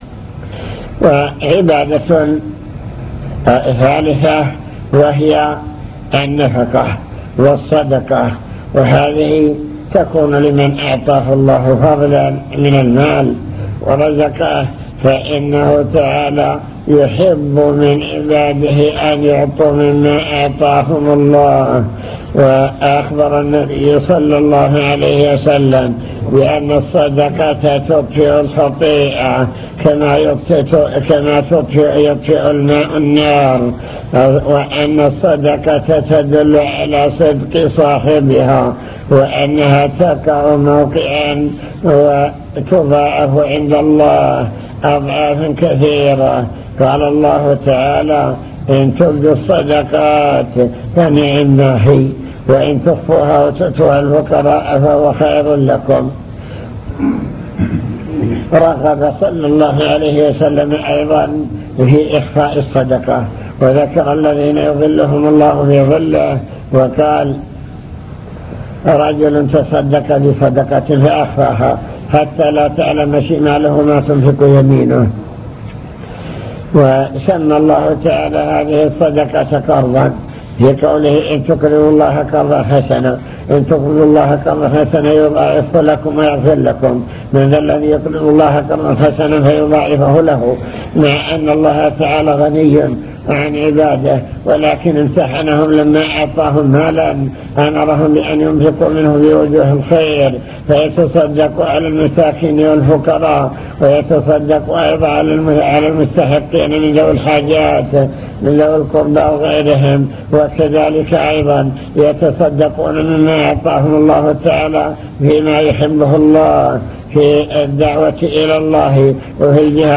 المكتبة الصوتية  تسجيلات - محاضرات ودروس  نوافل العبادات وأنواعها العبادات الفعلية